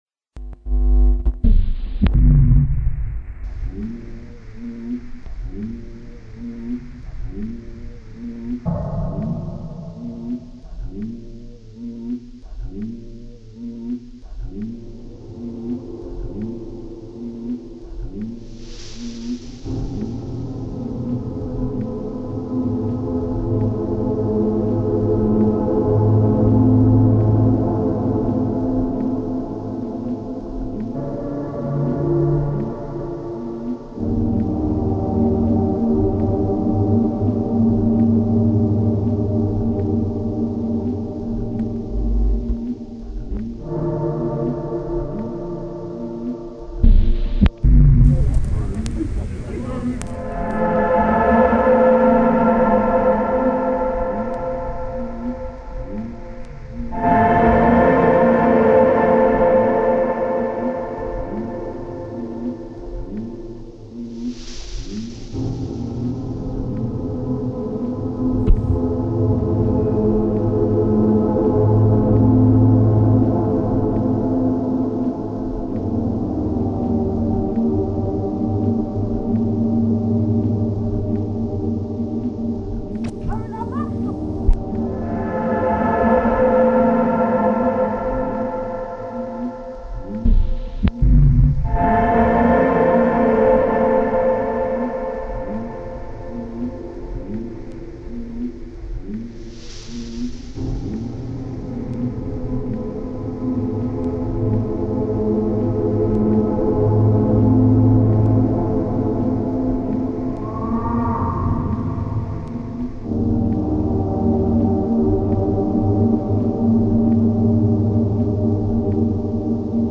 [2.3mb] Composed and reconstructed using audio from this cassette, found at the bottom of Stamford Hill, London in March 2008.